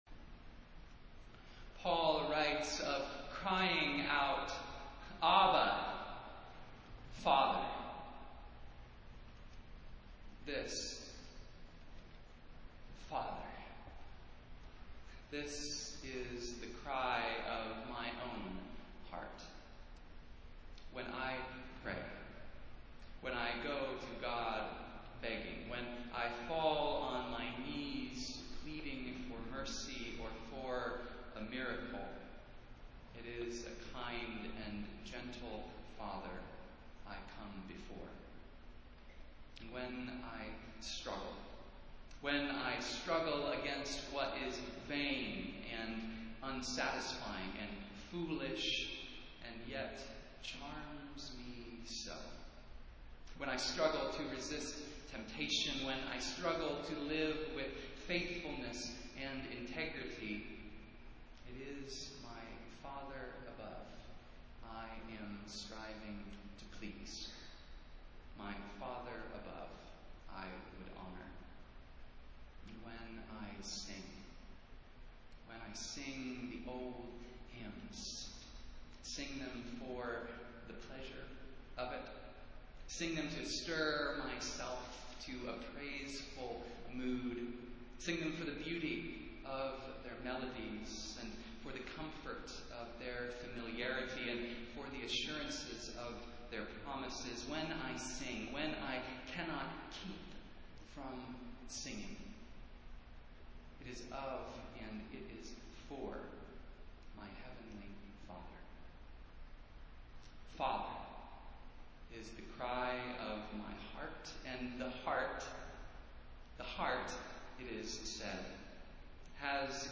Festival Worship - Seventh Sunday after Pentecost